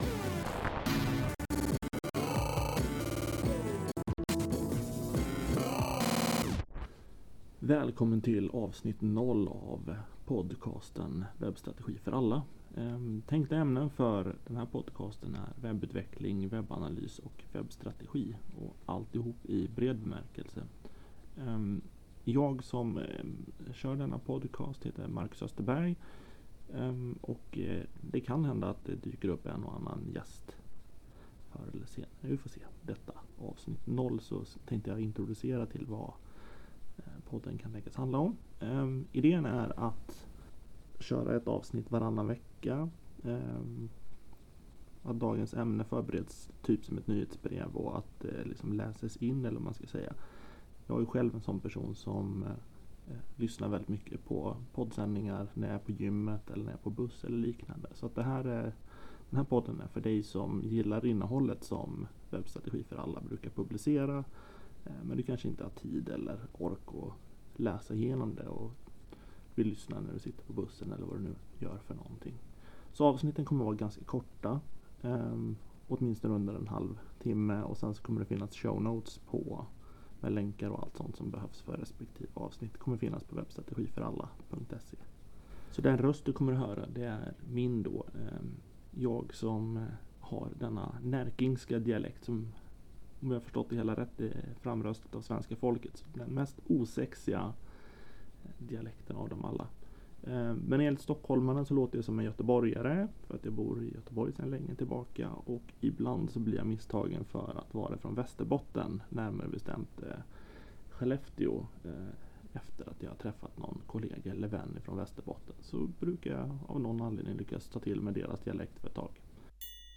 Detta första avsnitt är en inläst version av bloggposten som jämför Epub och Pdf för digitala trycksaker. Epub är ett mer webblikt format, egentligen bara en paketerad webbplats rent tekniskt.